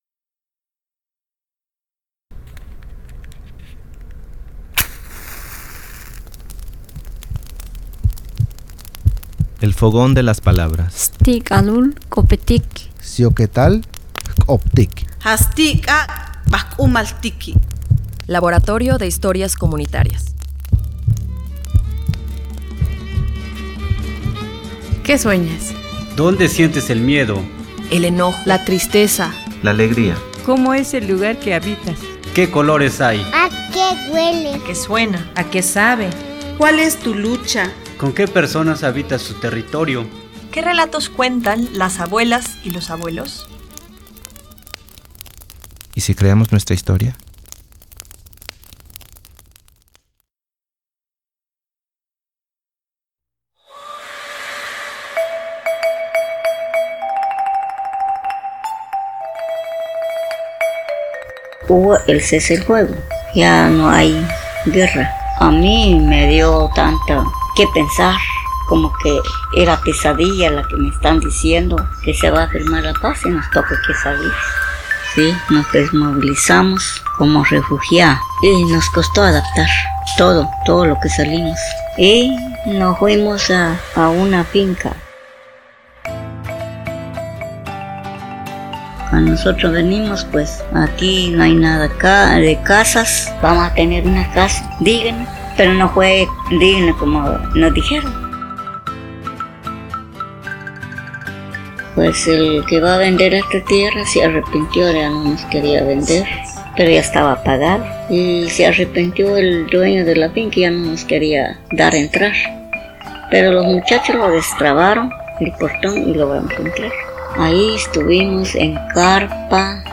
Sueños, memorias y relatos narrados por las y los habitantes de la Cooperativa Nuevo Horizonte durante el Laboratorio de historias comunitarias.